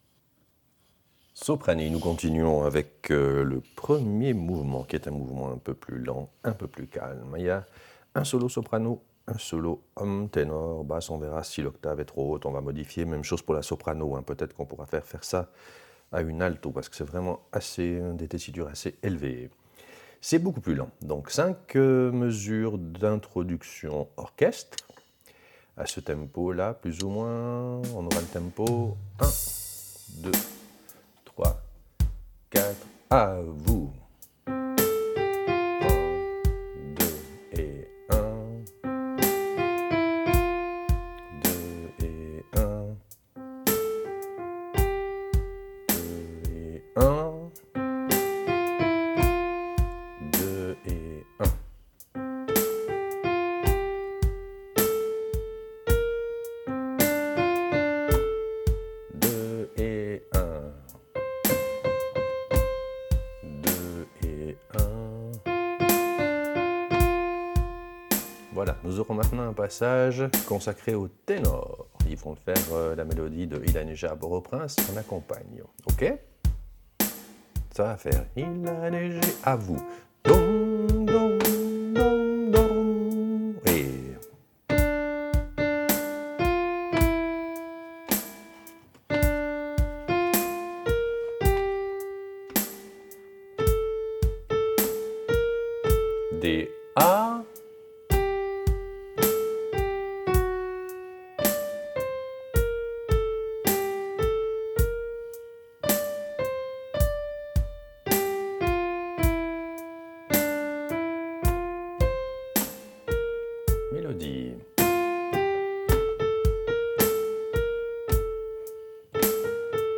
Répétition SATB4 par voix
Soprano